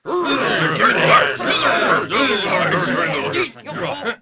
1 channel
hubbub07.wav